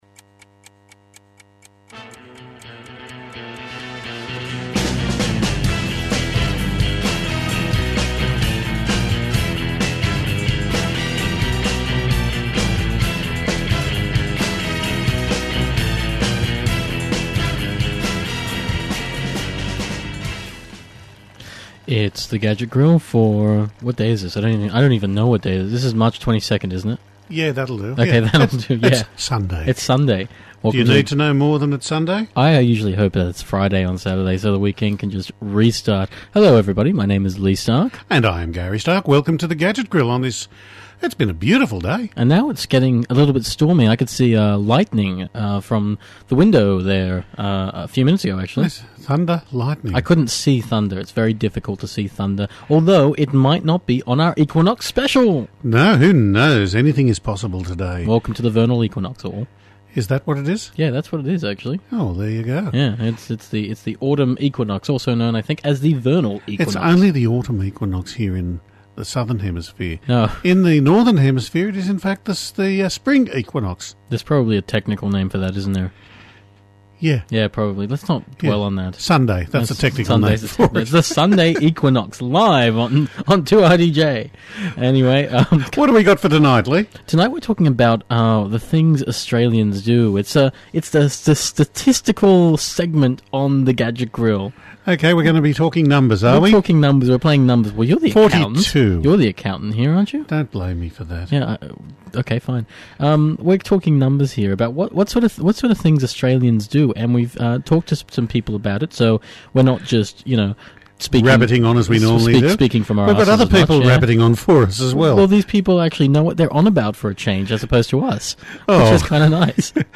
Hot off the presses is a new survey about how people all around the world view technology. We got an interview with one of the people behind it in this segment so if you’re into understanding all of what we are, tune in for that.